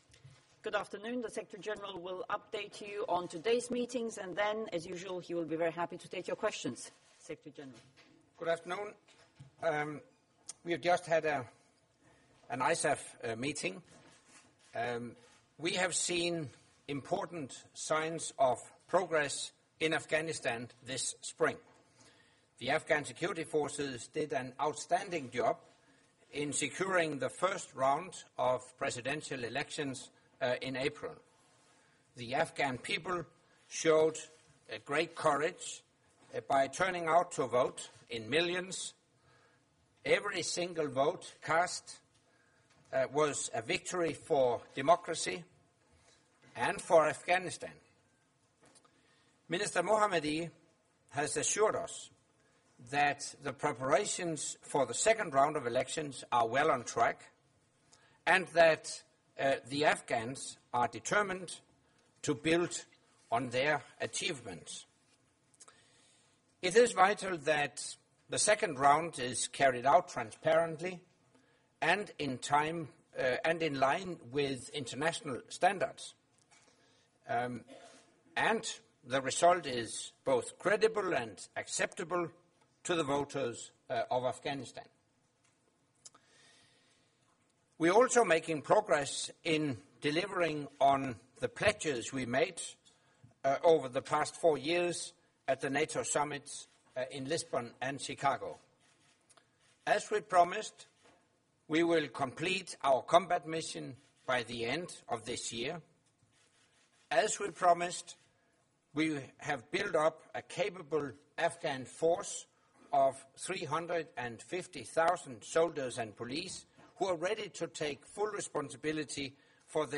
Press conference by NATO Secretary General Anders Fogh Rasmussen following the second day of meetings of NATO Defence Ministers